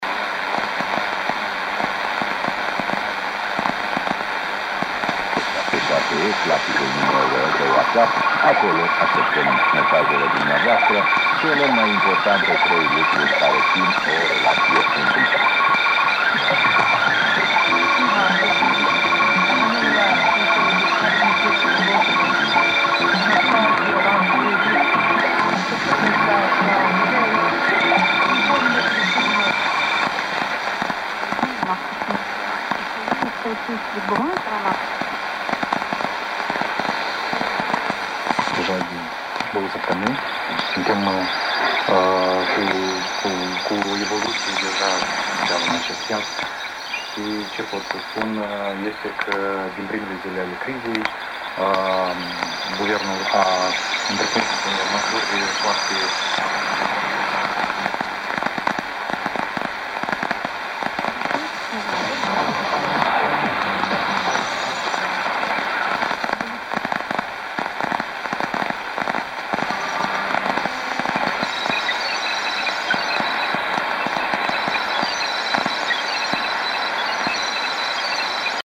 DNA-similar signal with radiostation 2
Начало » Записи » Радиоcигналы на опознание и анализ
dna-similar_signal_with_radiostation_2.mp3